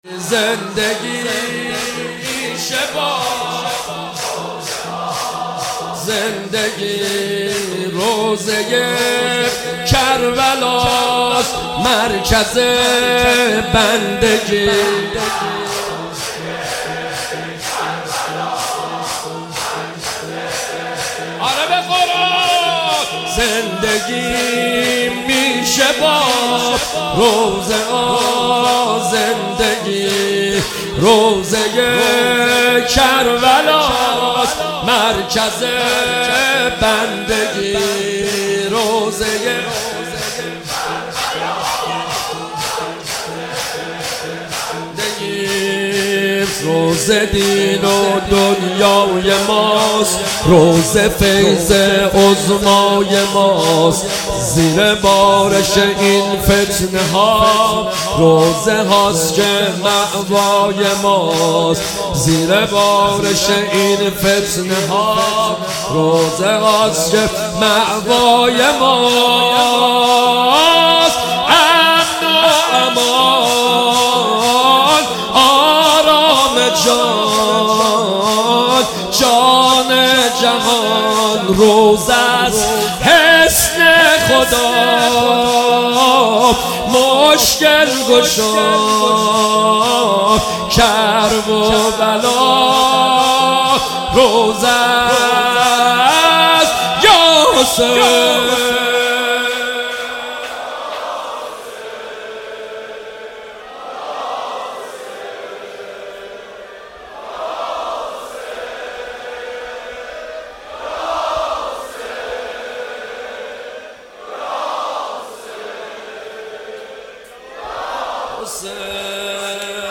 مداحی جدید
شب سوم محرم 1400
شور